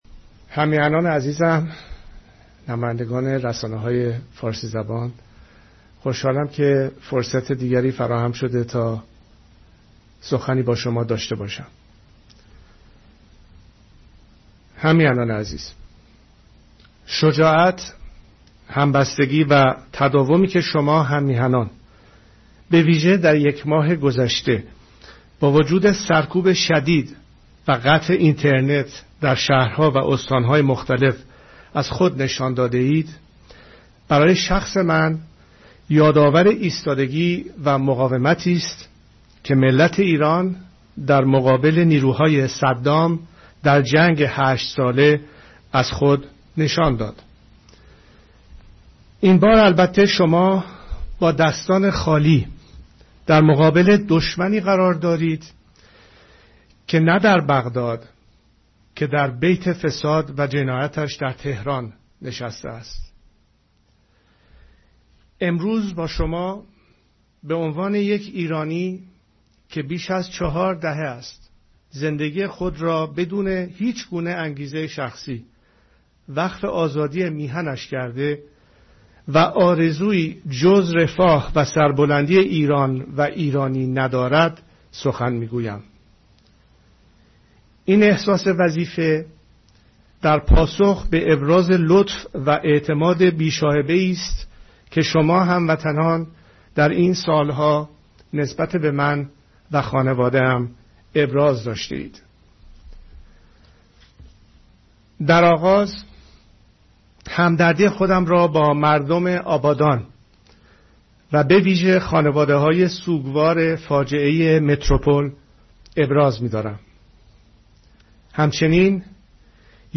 سخن رضا شاه دوم با ایرانیان